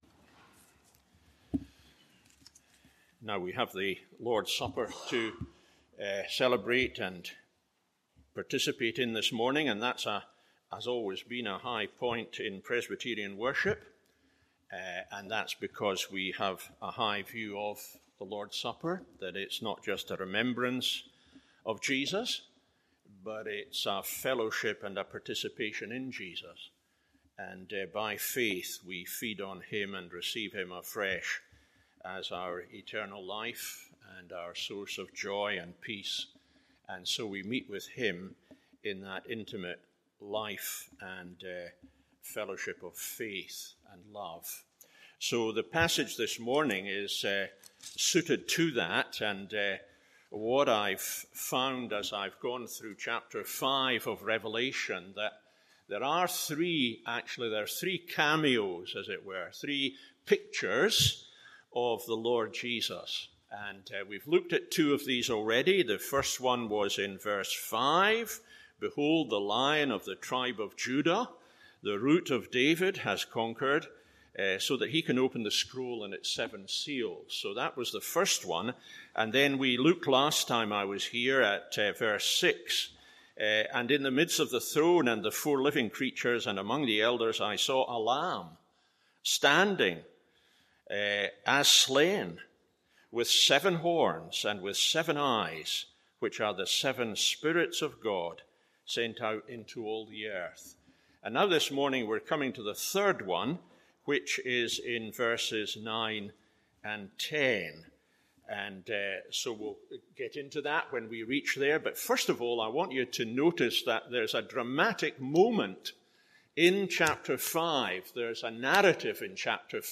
MORNING SERVICE Rev 5:7-14…